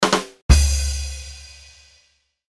Rimshot.mp3